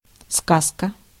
Ääntäminen
IPA: [kɔ̃t]